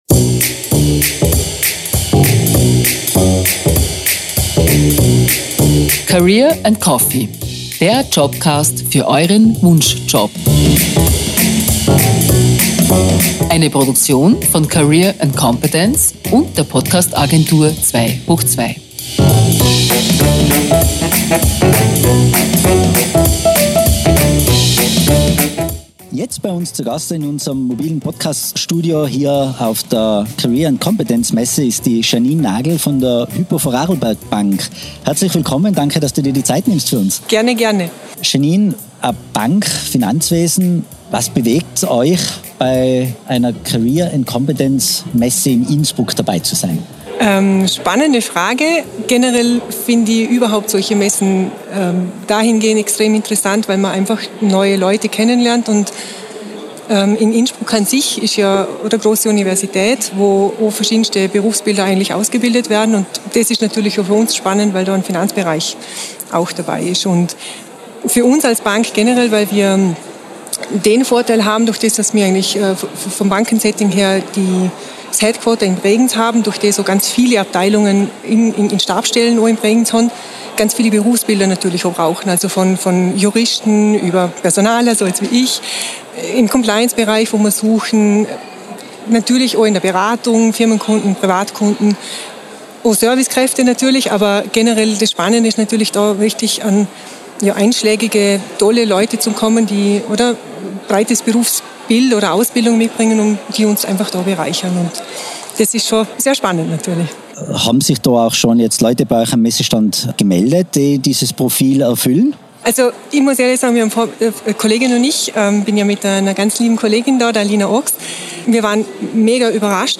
Livemitschnitt von der Career & Competence-Messe mit
Masterlounge in Innsbruck am 4. Mai 2022.